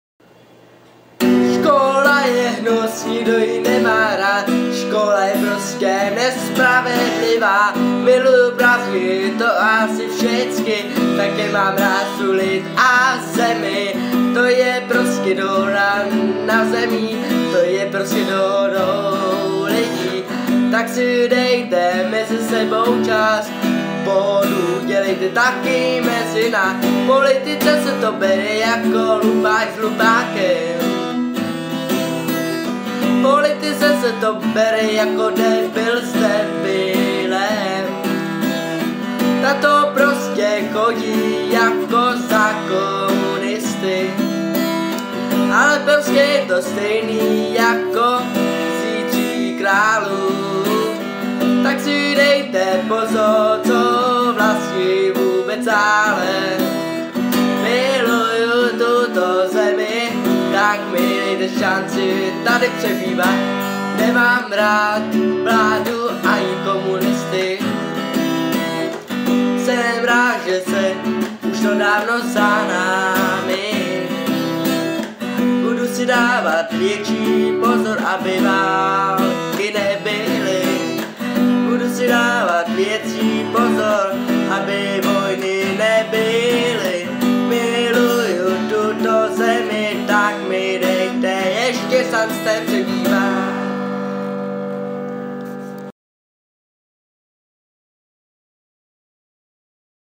Žánr: World music/Ethno/Folk
Folkové hudební album